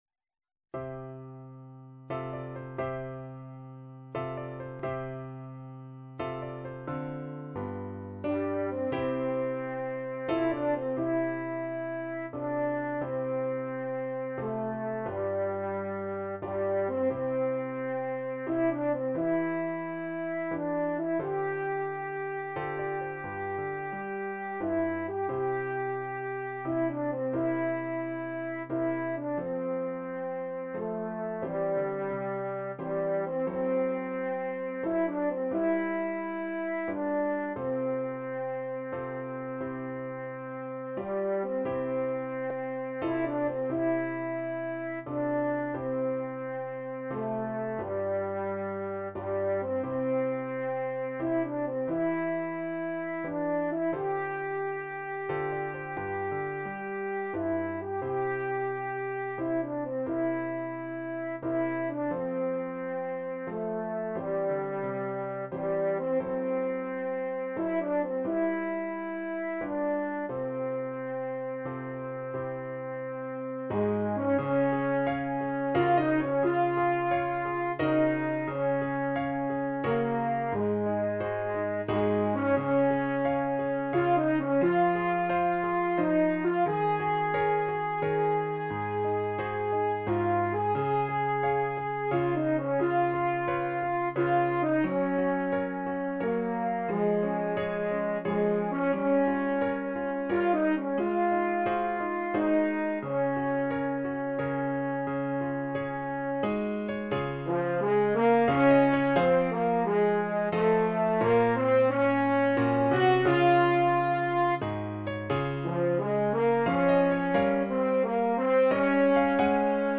Gattung: Horn und Klavier